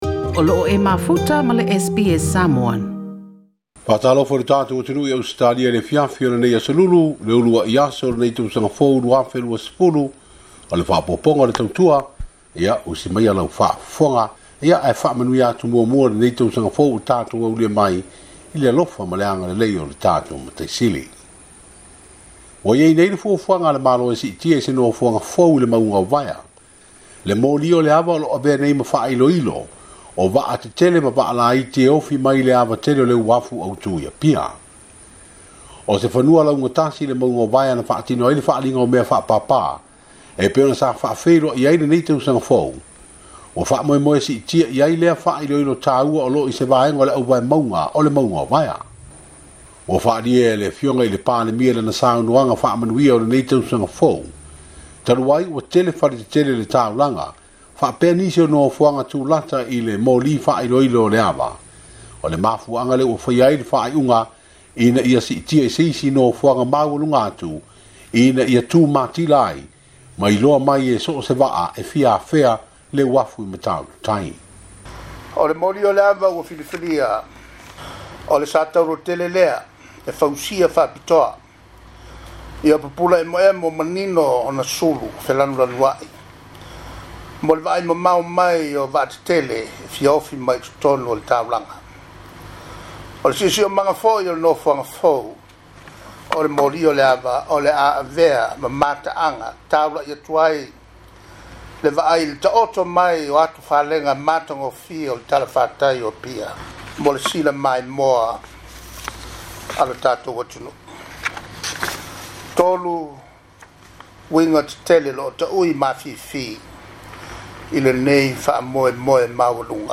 Uluai lipoti o talafou mai Samoa mole 2020